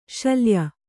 ♪ śalya